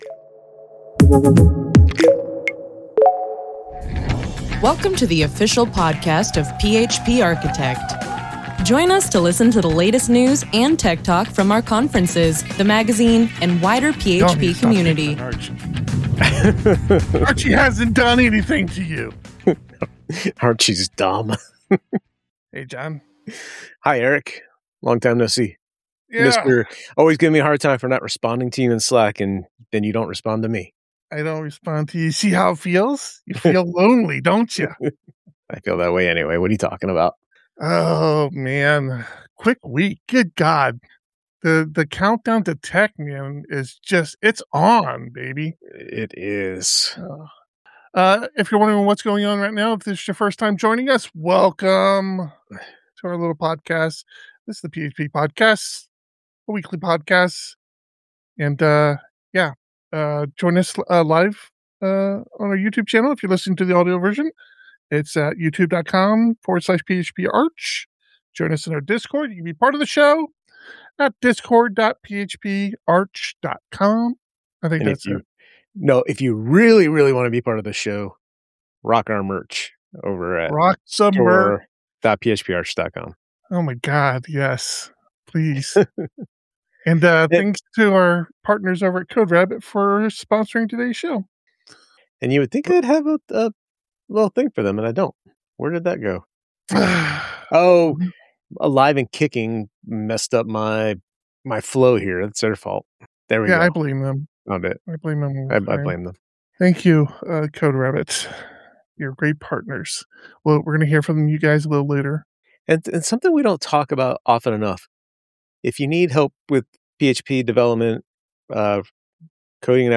The PHP Podcast streams live, typically every Thursday at 3 PM PT.